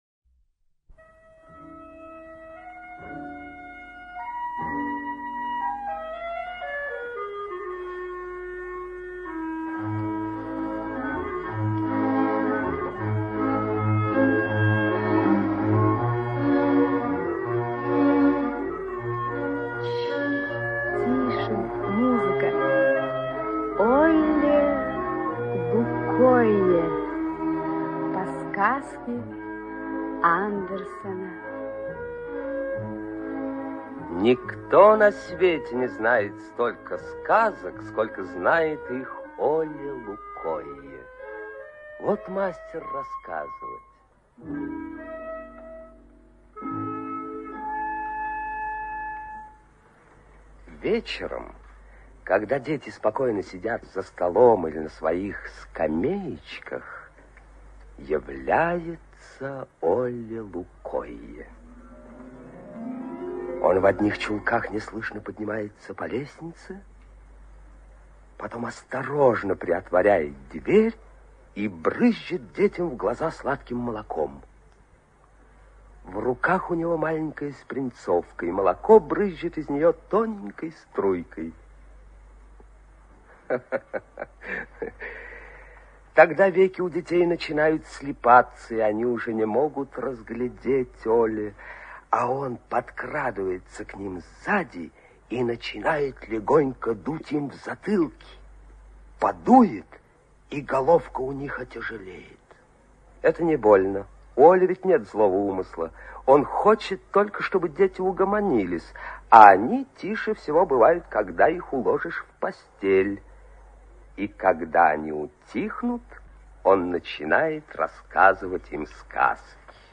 Аудио сказка Оле-Лукойе.